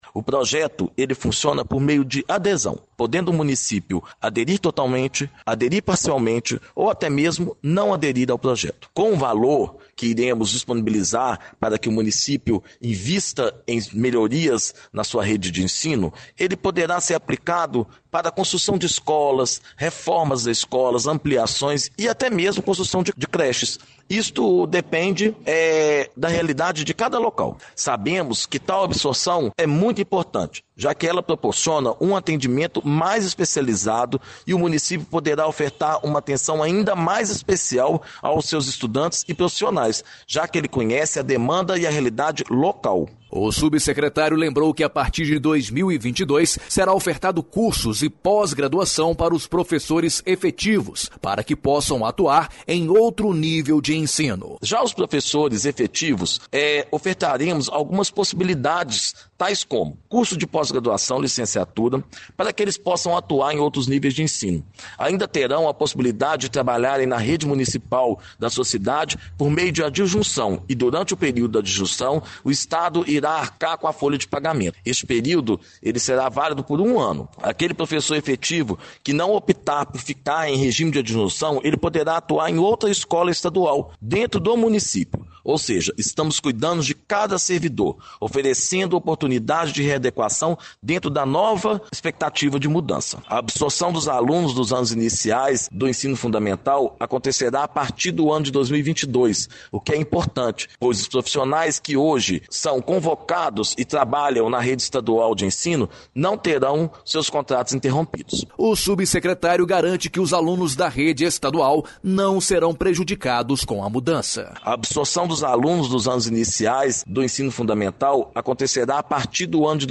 Subsecretário de Articulação Educacional da SEE/MG, Igor de Alvarenga Rojas